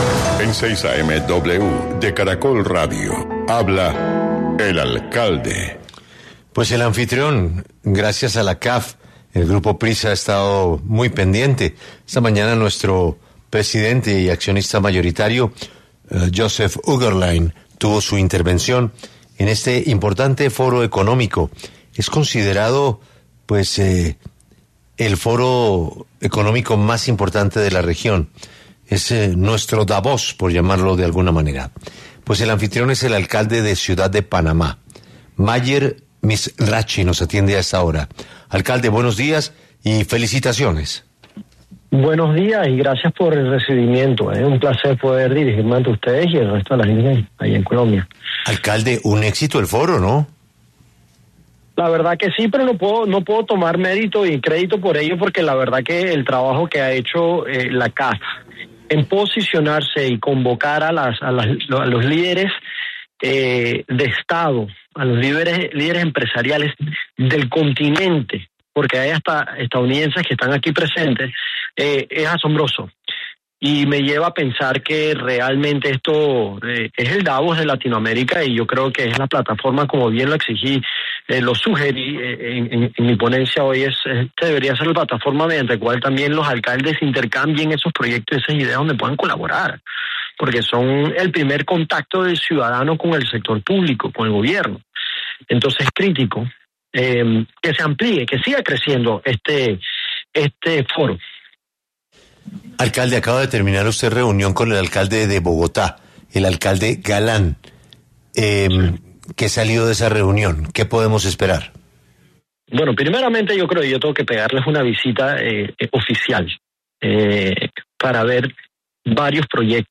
En entrevista con 6AM W de Caracol Radio, el alcalde Mizrachi reconoció el trabajo de CAF en posicionar y convocar a líderes de estado y empresariales del continente, incluyendo a participantes estadounidenses, calificando su labor de “asombrosa”.